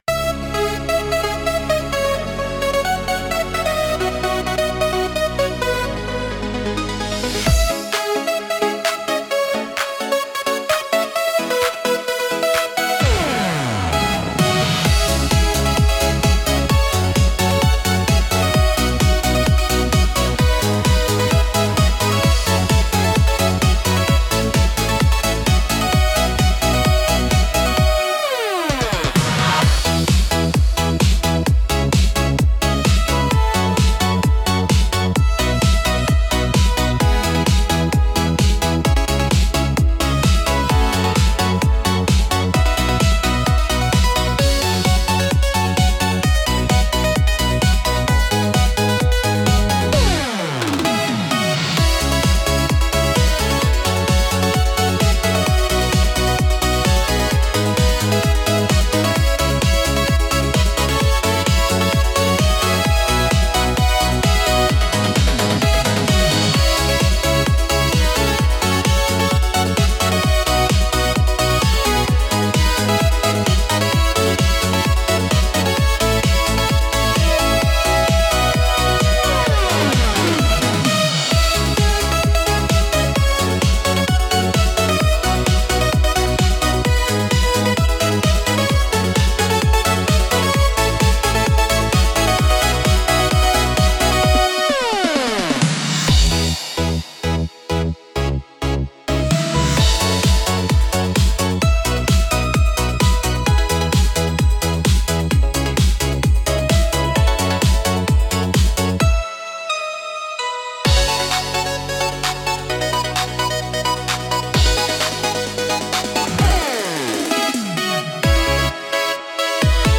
Instrumental - Retro Future Funk 2.38